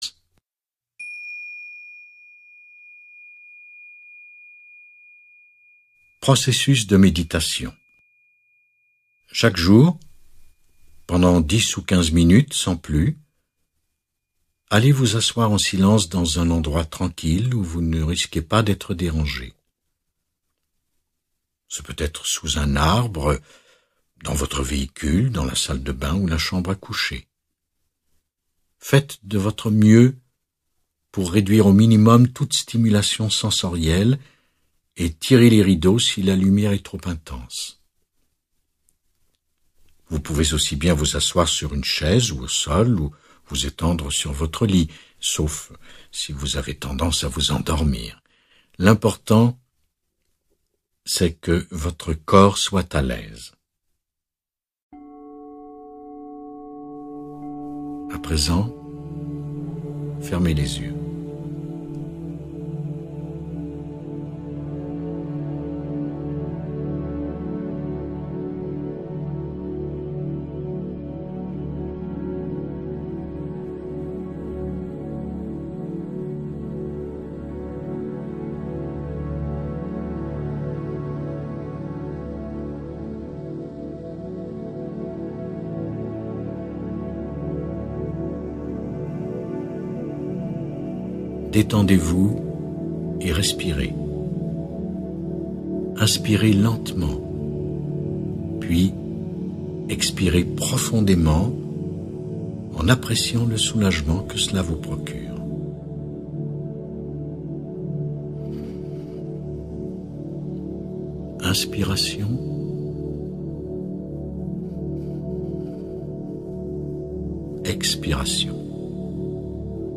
10-min-de-méditation-de-lâcher-prise.mp3